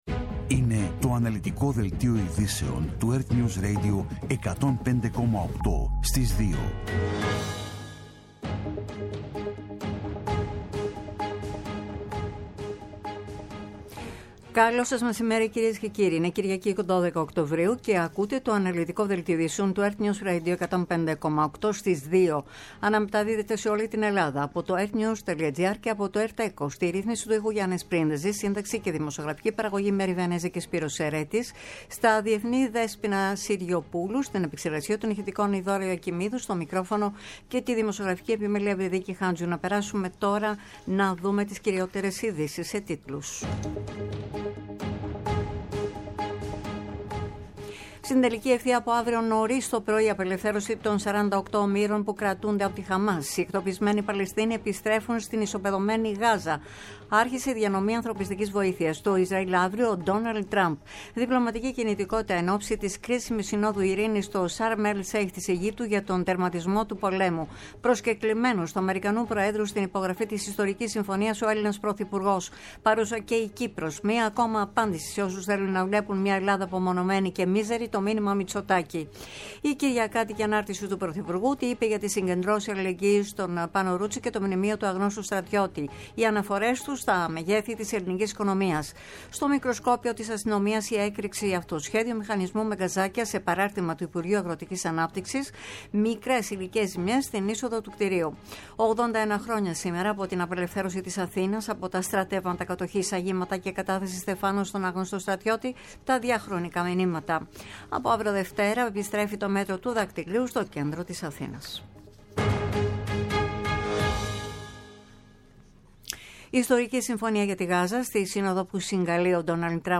Το κεντρικό ενημερωτικό μαγκαζίνο στις 14.00.
Με το μεγαλύτερο δίκτυο ανταποκριτών σε όλη τη χώρα, αναλυτικά ρεπορτάζ και συνεντεύξεις επικαιρότητας.